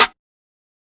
Metro Bright Perc.wav